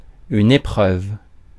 Ääntäminen
US : IPA : [ˈtʃɹaɪ.əl]